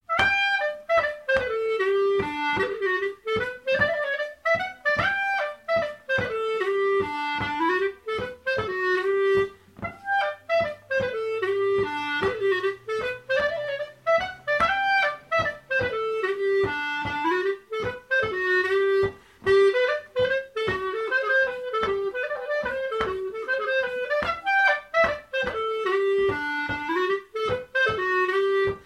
Polskor
Inspelad: Börjesgården- 2001